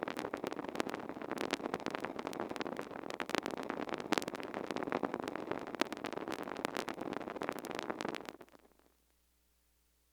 digitone synthed vinyl patch
idk why but I get a kick out of synthesizing things that should be sampled instead